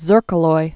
(zûrkə-loi)